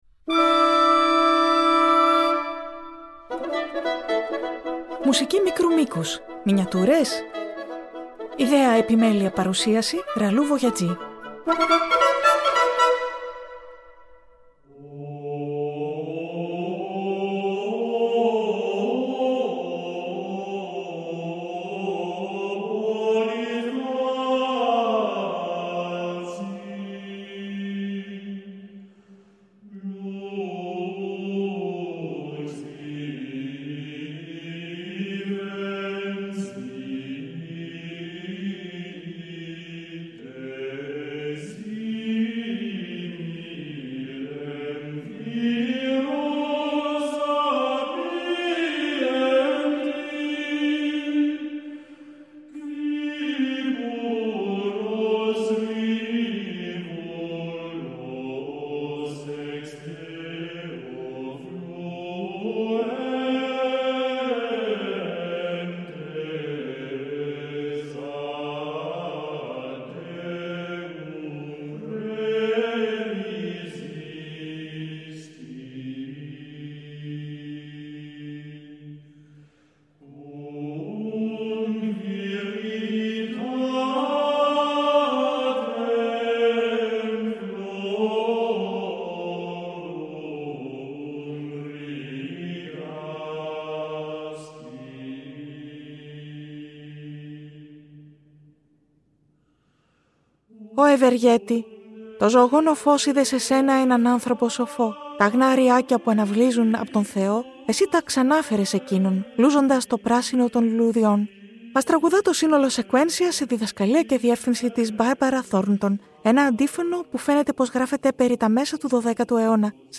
Οκτώ Μουσικά Έργα Μικρής Διάρκειας
Τι κοινό έχουν δύο αντίφωνα, ένα σκέρτσο, μια μουσική εικόνα, ένα αργό βάλς, ένα τραγούδι με λόγια, ένα χωρίς: μια περιήγηση λίγο διαφορετική, σε μια αίθουσα του φανταστικού μουσείου μουσικών έργων όπου ορισμένα από τα στοιχεία των έργων παραμένουν άγνωστα μέχρι το τέλος της περιήγησης.